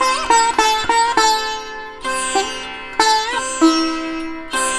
100-SITAR2-L.wav